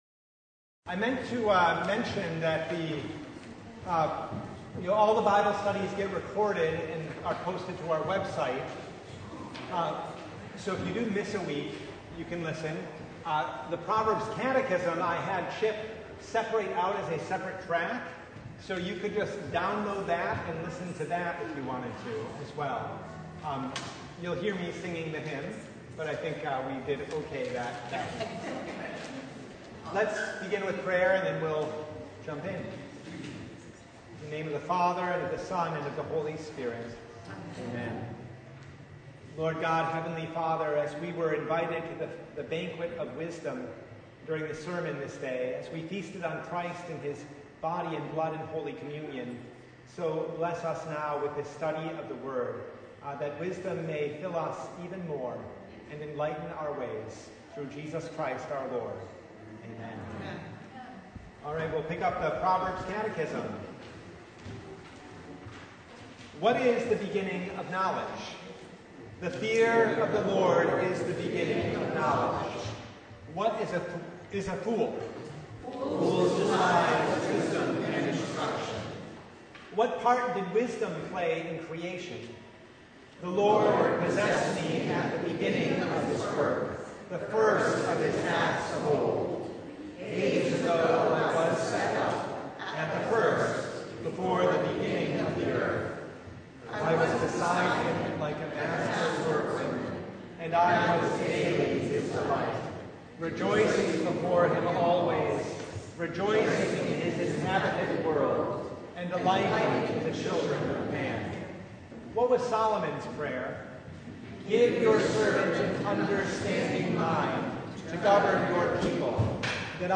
Proverbs 3 Service Type: Bible Hour Topics: Bible Study « An Invitation to Wisdom’s Feast The Third Sunday in Martyrs’ Tide